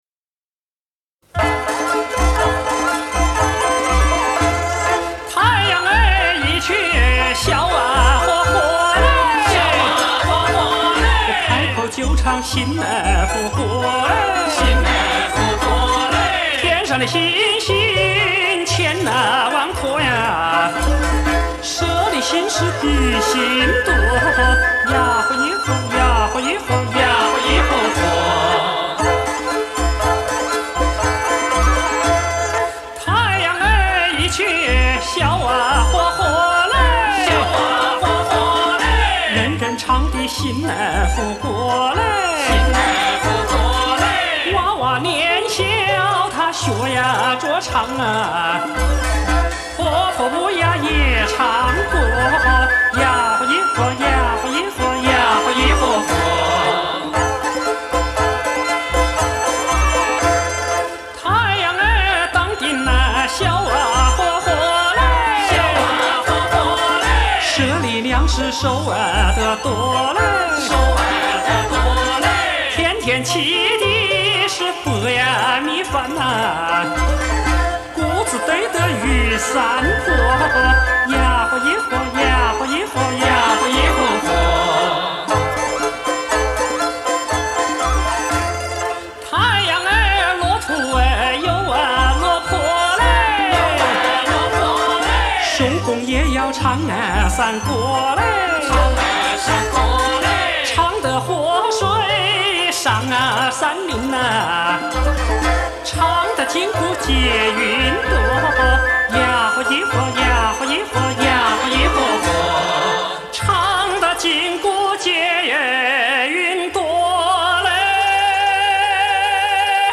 湖北民歌